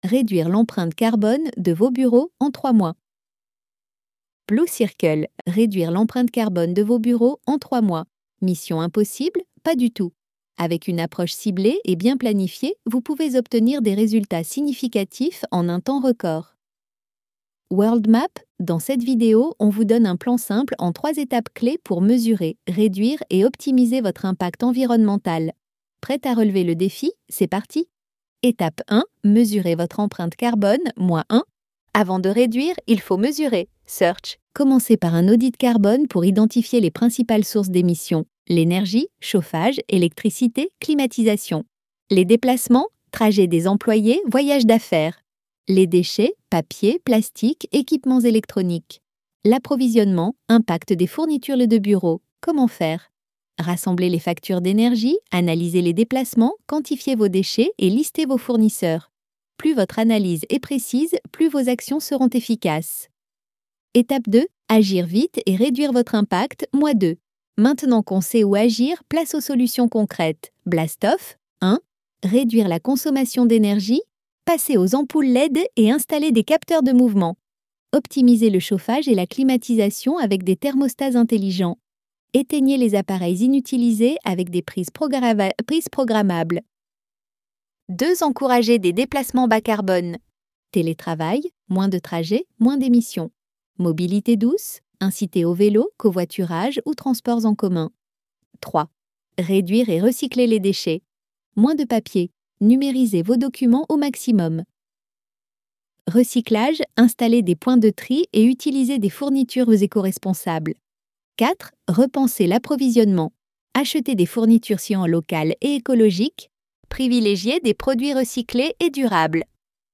ElevenLabs_Chapter_2.mp3